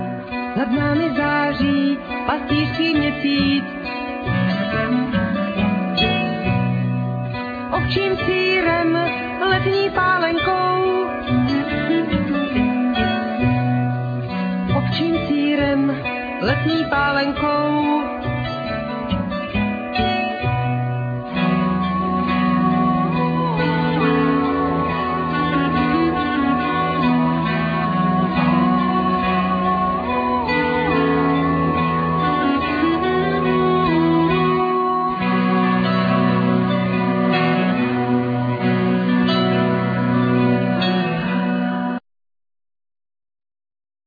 Vocal,Violin,Okarina
Mandolin,Guitar
Saxophone,ClarinetXylophone,Bonga
Cello,Violin